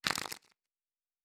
Cards Shuffle 2_04.wav